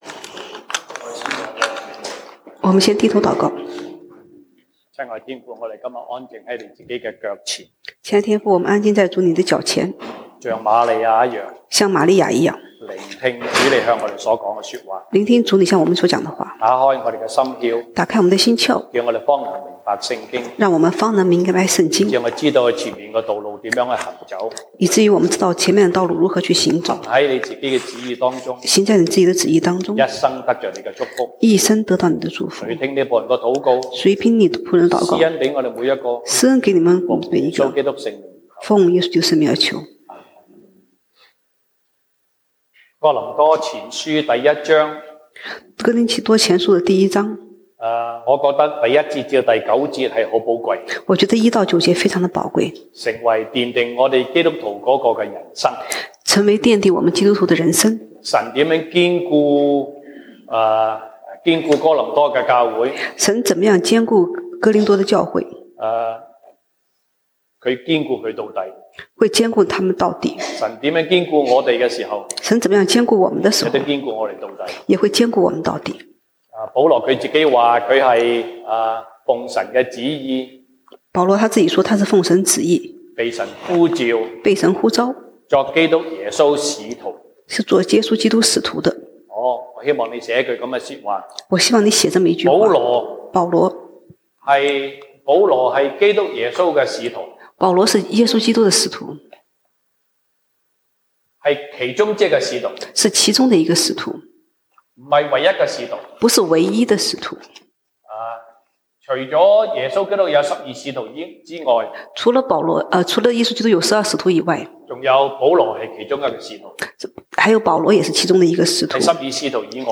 西堂證道(粵語/國語) Sunday Service Chinese: 堅固你們的神,必定堅固你們到底
Passage: 歌林多前書 1 Corinthians 1:1-9 Service Type: 西堂證道(粵語/國語) Sunday Service Chinese